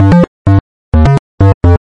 基斯林舞蹈G2 DSHARP2 128 bpm
描述：bassline dance g2 dsharp2 128 bpm.wav
Tag: 最小 狂野 房子 TECHNO 配音步 贝斯 精神恍惚 舞蹈 俱乐部